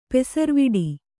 ♪ pesarviḍi